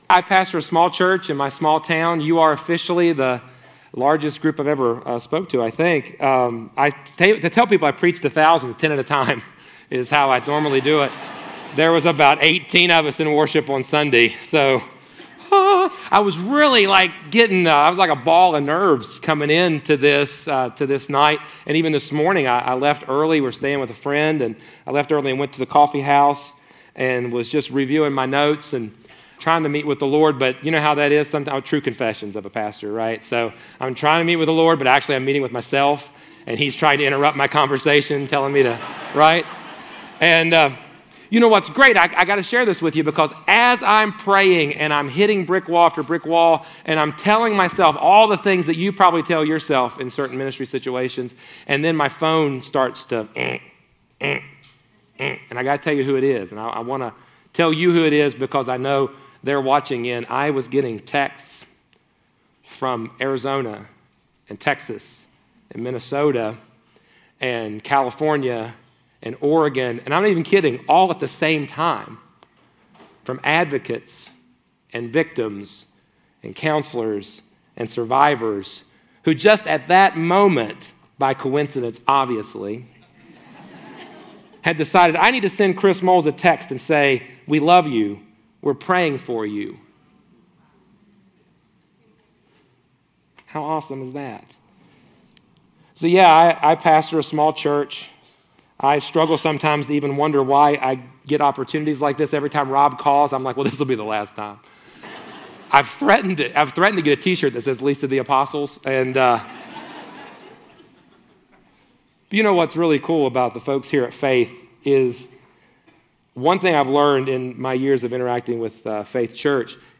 This is a session from a Biblical Counseling Training Conference hosted by Faith Church in Lafayette, Indiana.